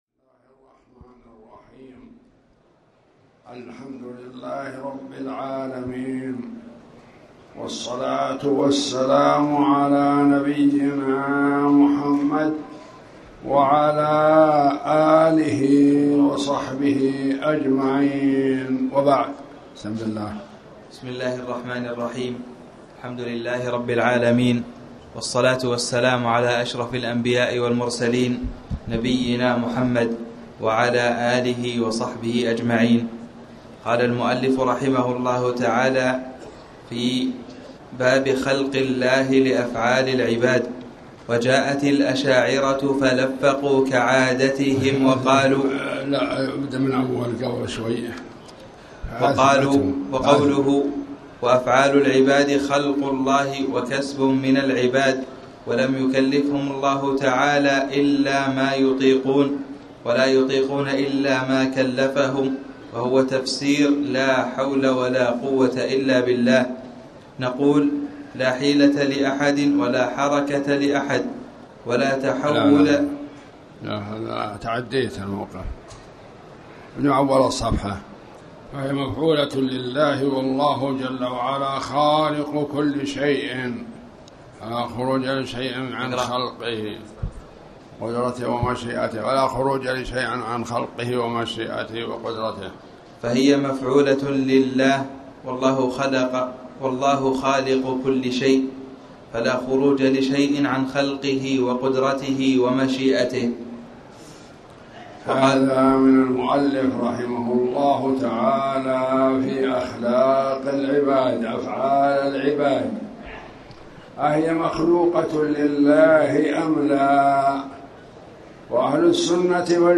تاريخ النشر ١ ربيع الثاني ١٤٣٩ هـ المكان: المسجد الحرام الشيخ